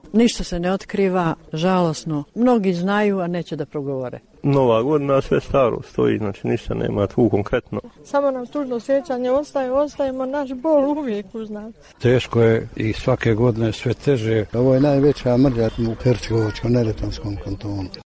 Izjave porodica